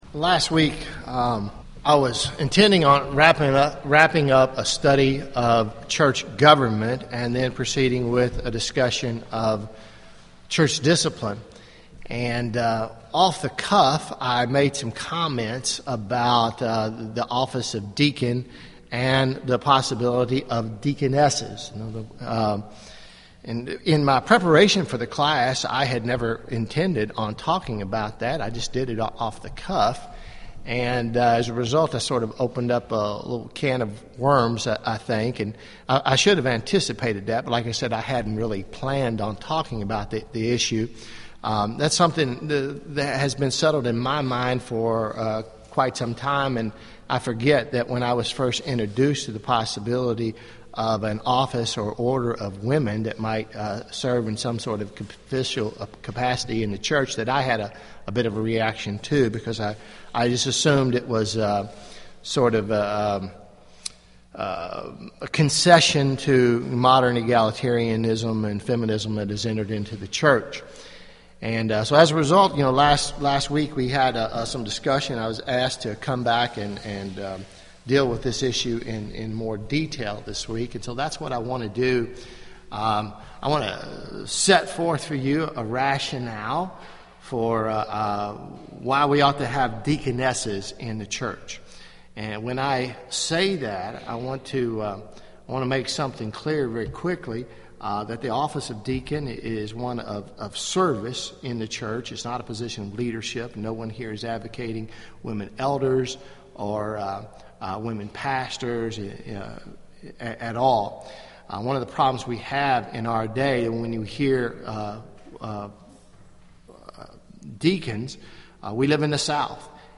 Deaconesses Sunday school class